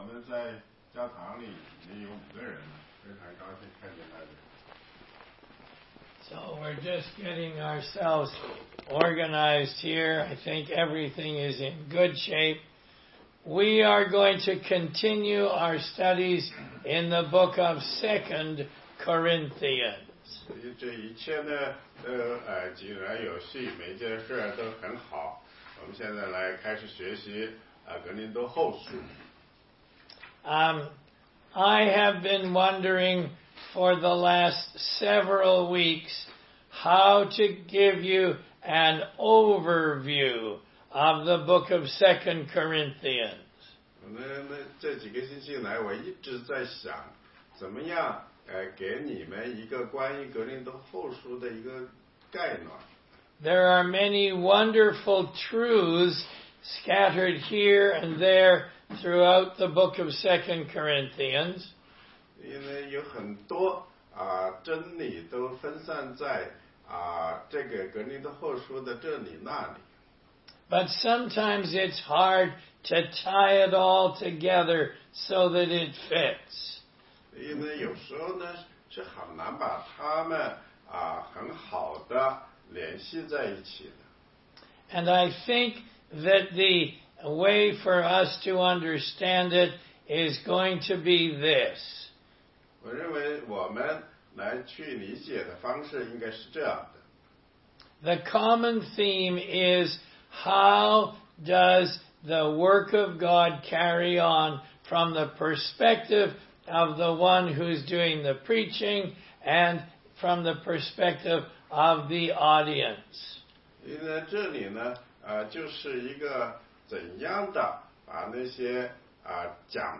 16街讲道录音 - 哥林多后书1章：赐各样安慰的神